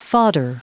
Prononciation du mot fodder en anglais (fichier audio)
Prononciation du mot : fodder